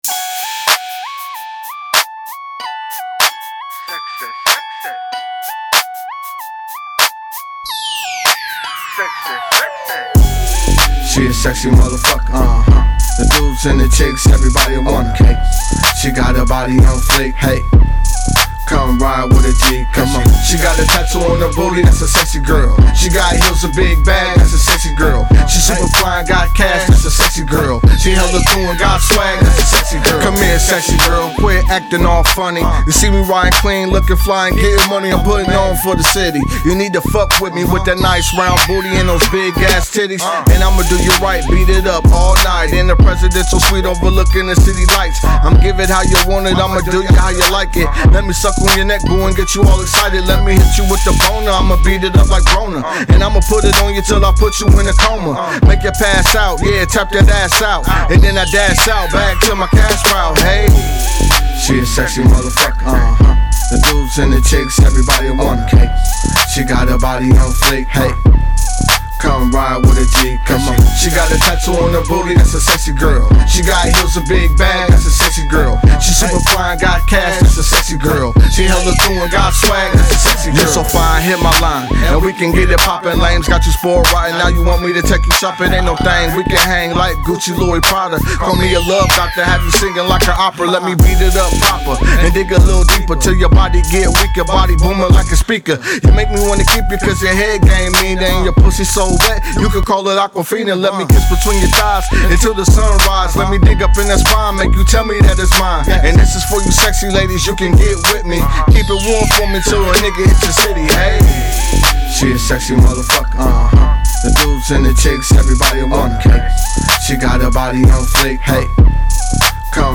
Description : Club Banger